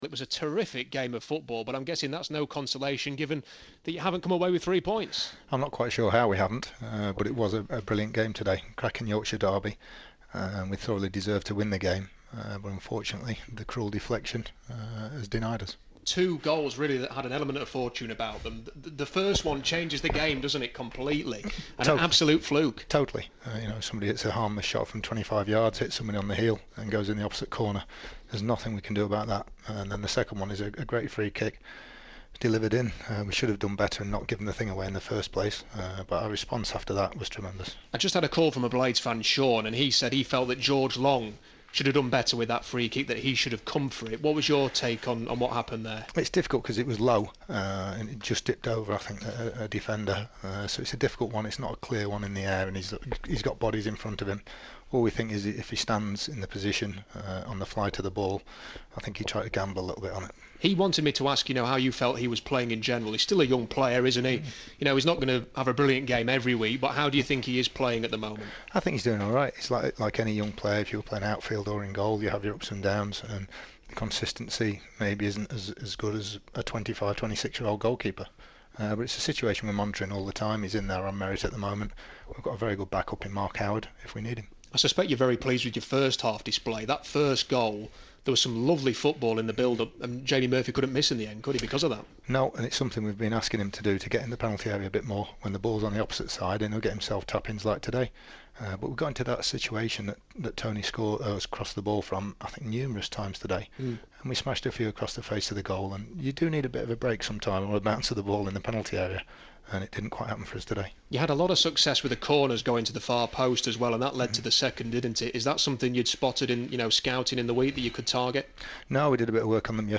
Interview: Nigel Clough speaking after today's 2-2 draw with Bradford